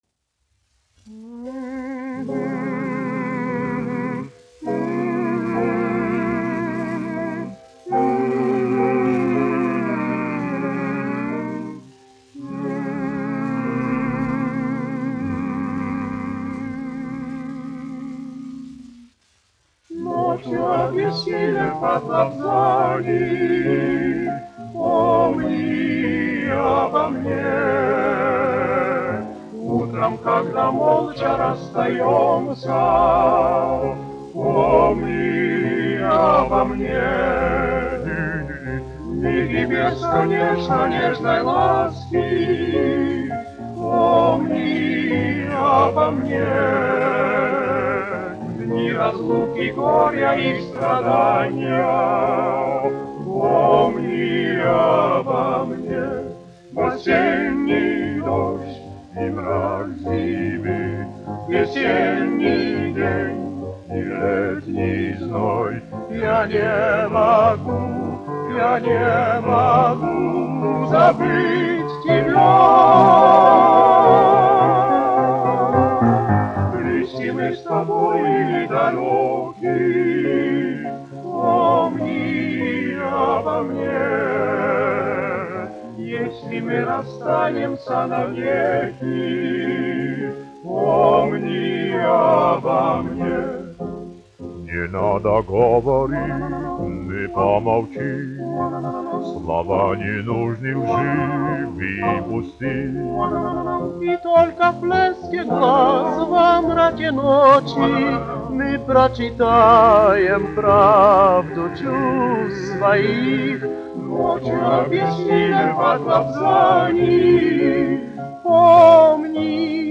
1 skpl. : analogs, 78 apgr/min, mono ; 25 cm
Vokālie ansambļi ar klavierēm
Skaņuplate